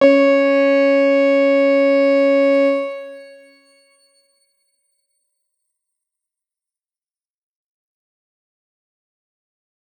X_Grain-C#4-pp.wav